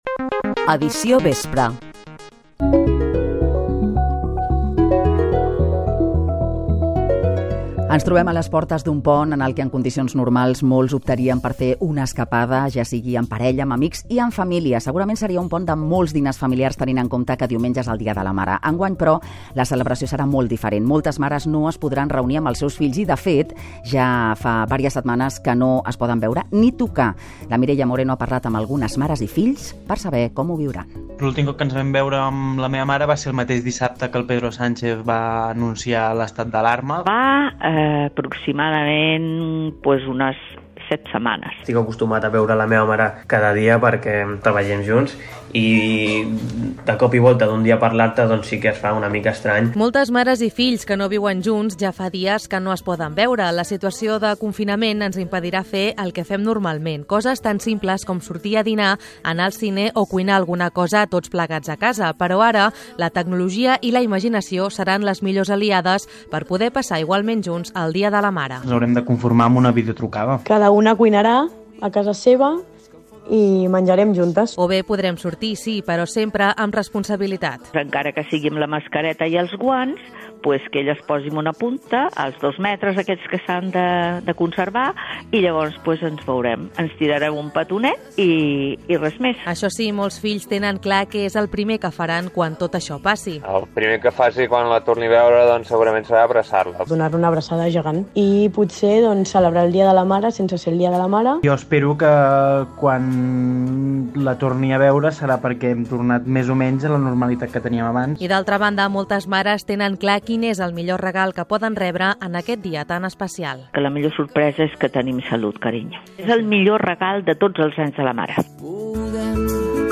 Indicatiu del programa, la celebració del Dia de la Mare serà molt diferent, degut a les restriccions per combatre la pandèmia de la Covid 19. Comiat
Informatiu